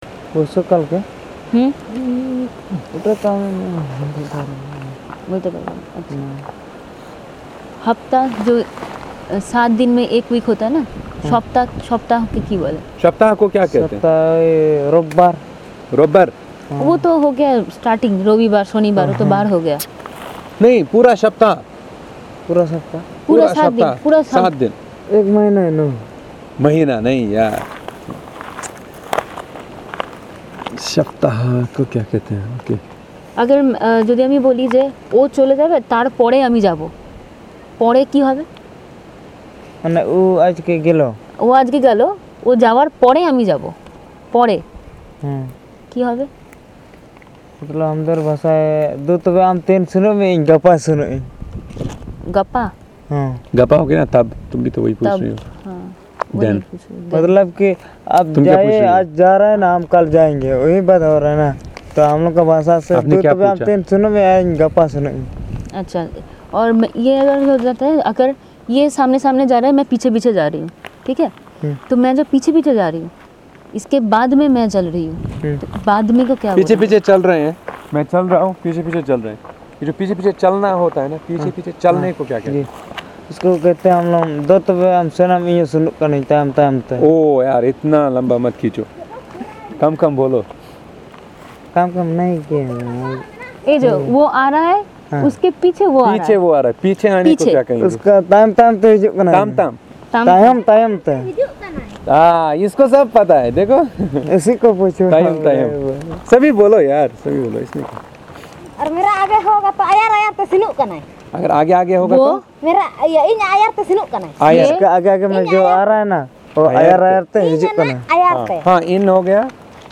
Elicitation of words related to time, interrogative verbs and other action verbs in Birhor
Discussion about the personal profile of the consultant also constitutes a part of the conversation.